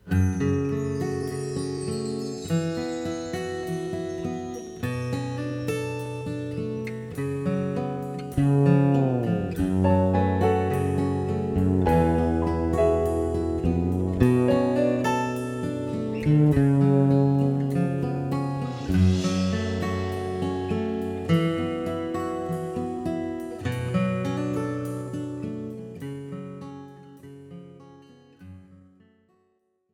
This is an instrumental backing track cover.
• Key – F# / G♭
• Without Backing Vocals
• No Fade